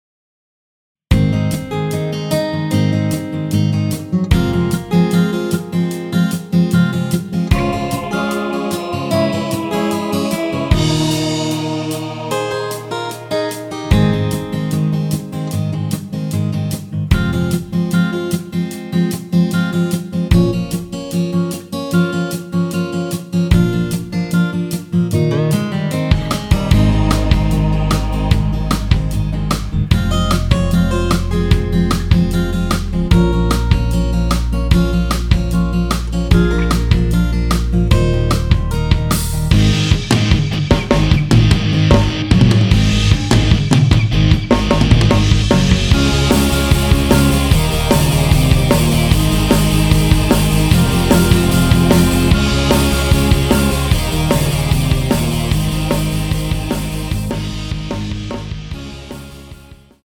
원키에서(-1)내린 MR입니다.
Ab
앞부분30초, 뒷부분30초씩 편집해서 올려 드리고 있습니다.
중간에 음이 끈어지고 다시 나오는 이유는